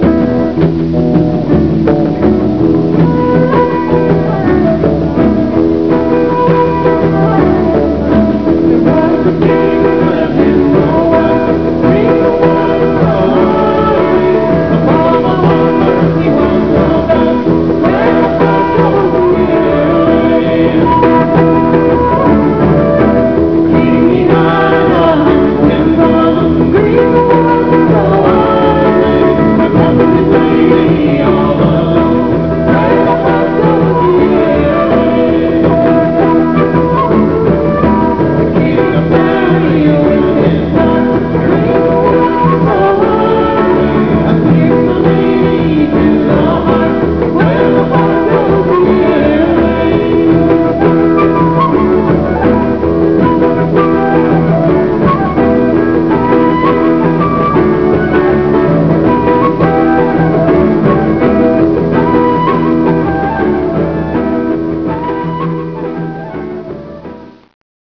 Celtic Rock band